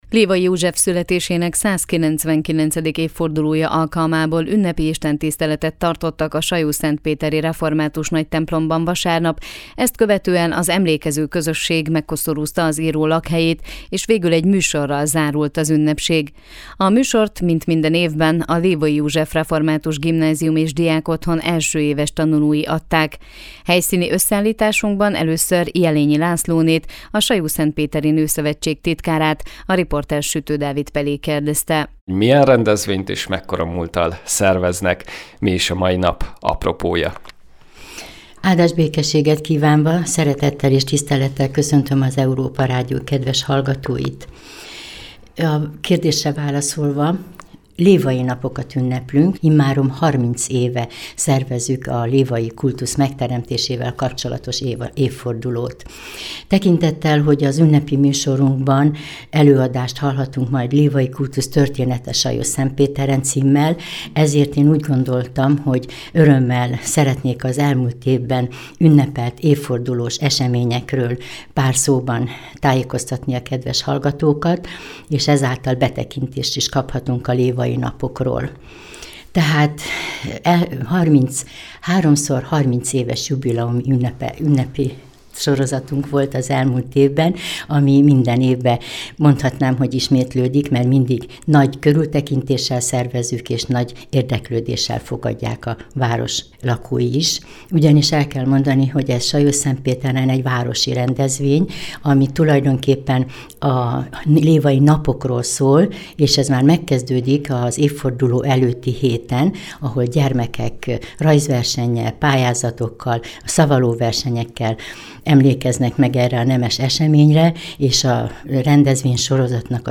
Lévay József születésének 199. évfordulója alkalmából ünnepi istentisztelet tartottak a Sajószentpéteri Református Nagytemplomban, ezt követően az emlékező közösség megkoszorúzta az író lakhelyét és végül egy műsorral zárult az ünnepség.